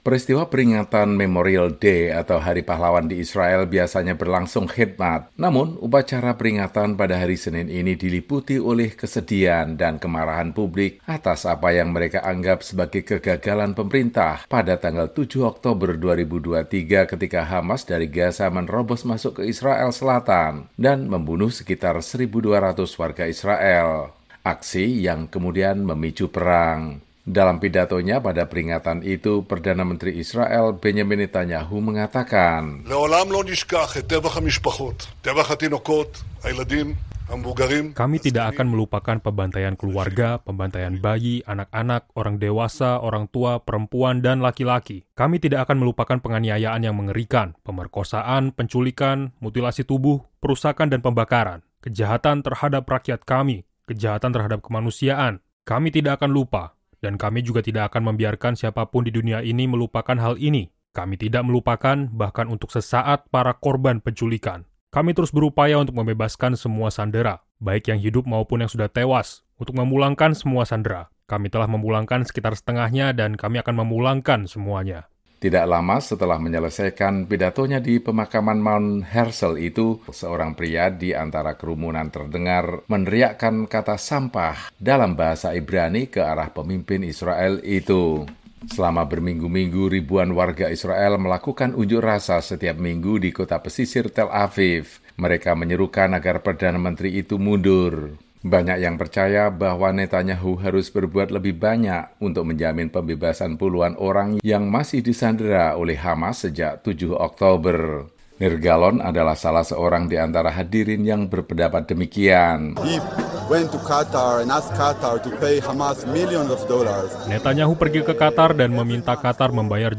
Dalam pidatonya pada peringatan Memorial Day (Hari Pahlawan) di Yerusalem, Senin (13/5), Perdana Menteri Israel Benjamin Netanyahu mengatakan tidak akan membiarkan dunia melupakan para korban dalam serangan 7 Oktober. Namun saat menyampaikan pidato itu dia dicemooh oleh sebagian keluarga sandera.
Tidak lama setelah menyelesaikan pidatonya di pemakaman Mount Herzl, seorang pria di antara kerumunan terdengar meneriakkan kata “sampah” dalam bahasa Ibrani ke arah pemimpin Israel itu.